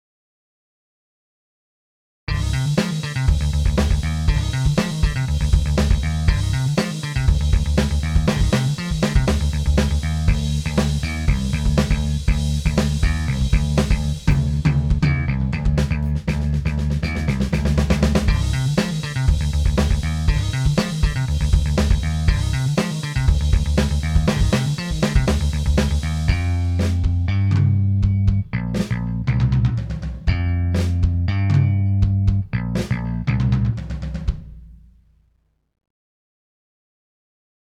Dabei handelt es sich um einen fünfsaitigen Bass, der sich vor allen Dingen für Rock und Metal eigenen soll.
Für die Klangbeispiele habe ich Bassläufe mit unterschiedlichen Presets eingespielt.